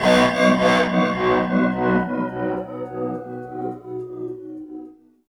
18 GUIT 3 -R.wav